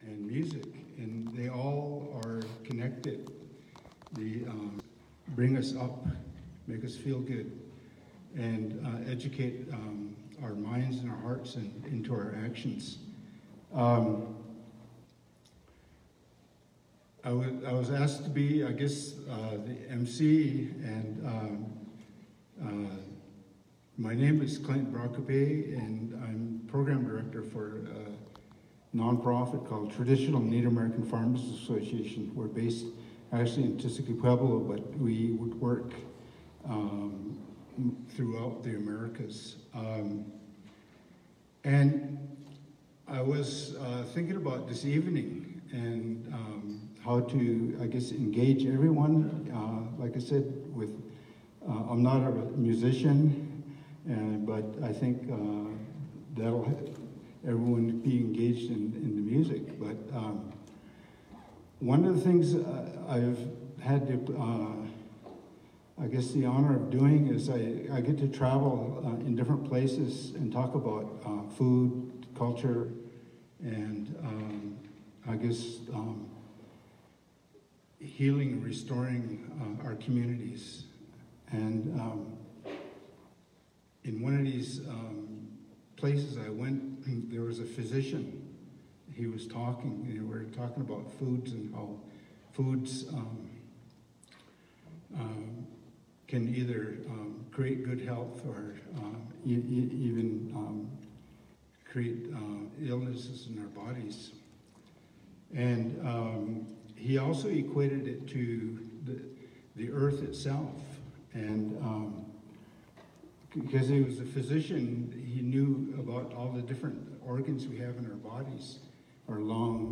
lifeblood: bootlegs: 2020-02-06: the alhambra theater in the scottish rite temple - santa fe, new mexico ("it takes a village" honor the earth benefit concert)
(captured from a facebook live stream)